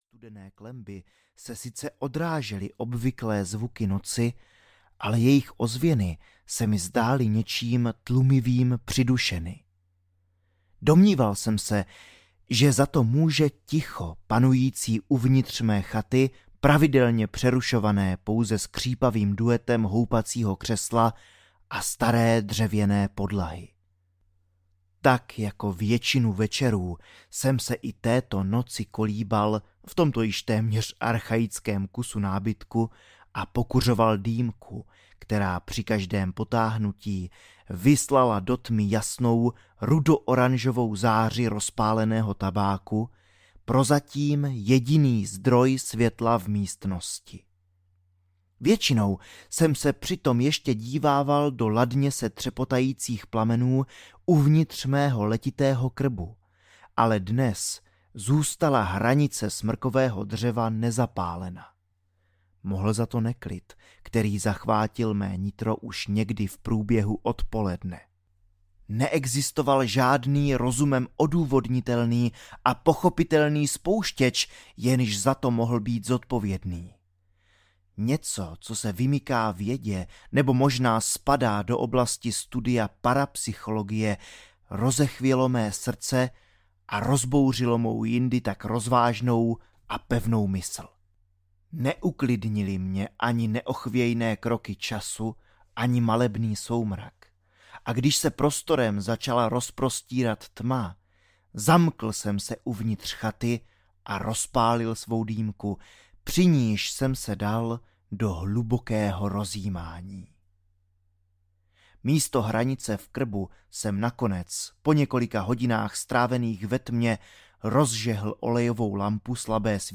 Hvozd audiokniha
Ukázka z knihy